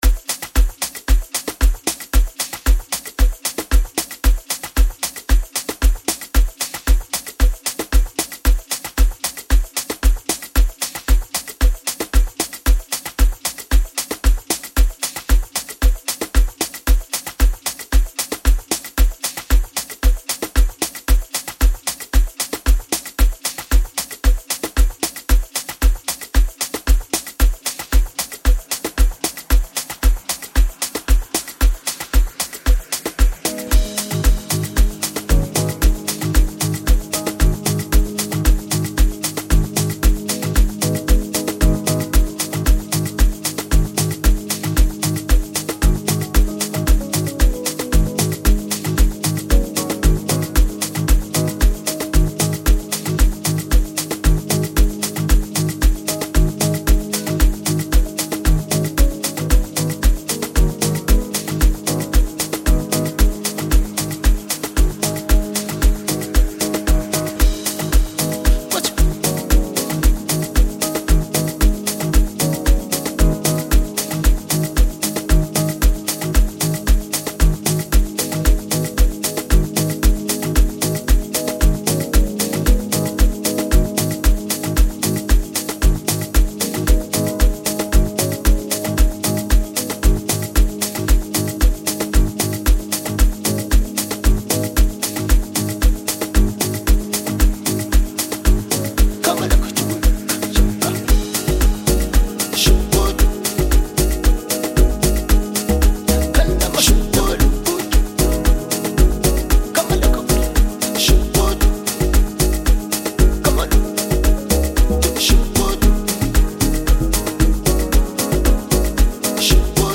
all instrumental track
Amapiano